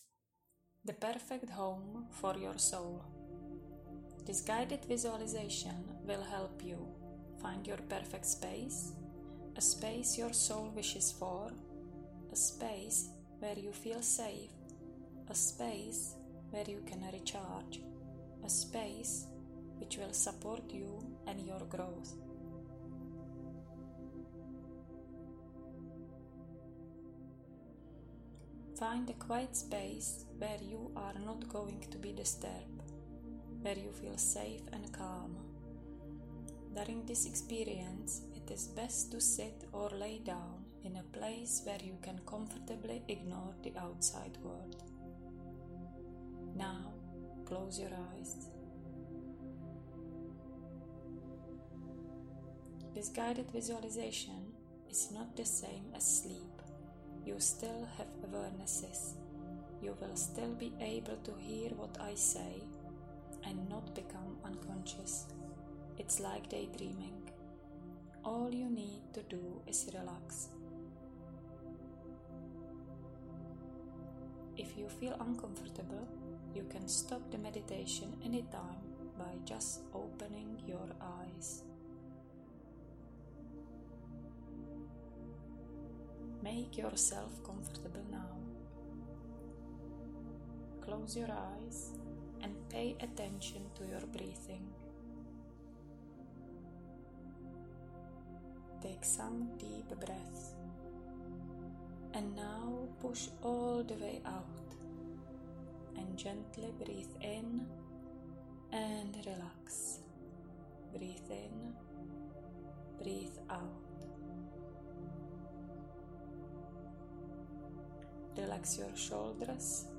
Many of my clients feel overwhelmed with choices, so I created a short Guided Meditation for Design Clarity.
Guided-med_Perfect-Home-for-Your-Soul.mp3